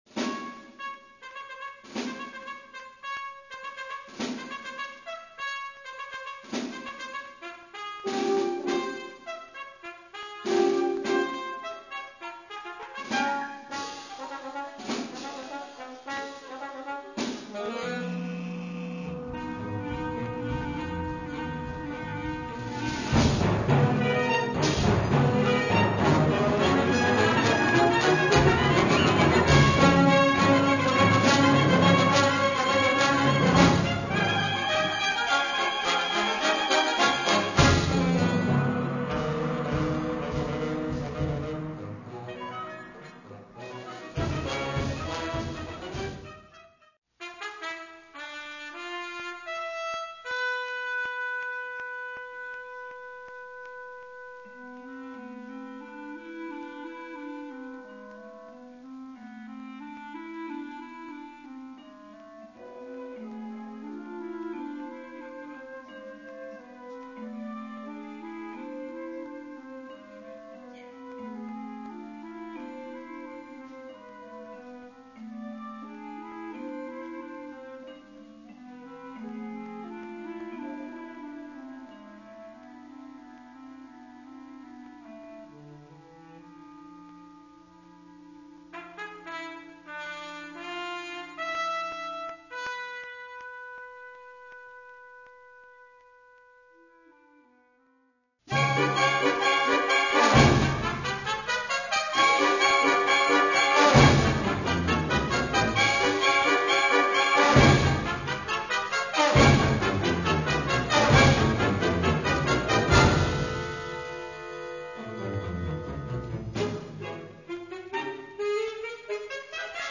Kategorie Blasorchester/HaFaBra
Besetzung Ha (Blasorchester)